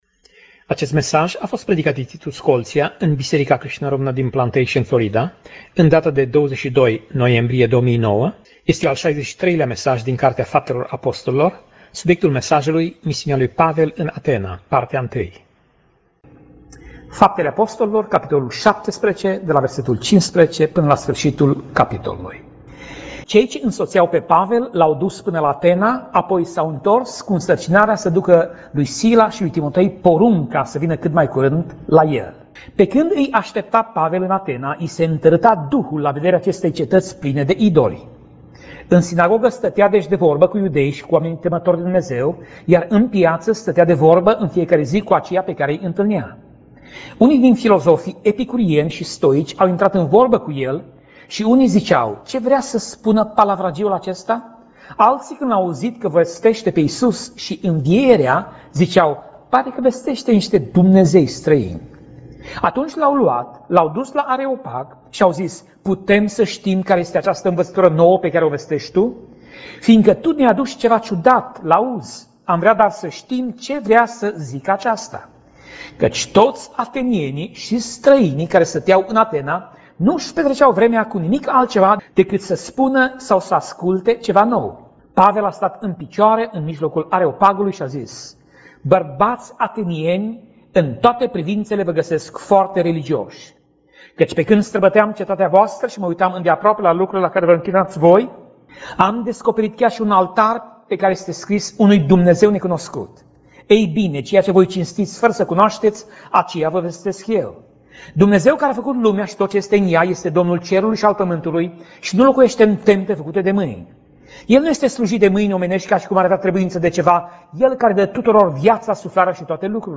Pasaj Biblie: Faptele Apostolilor 17:15 - Faptele Apostolilor 17:34 Tip Mesaj: Predica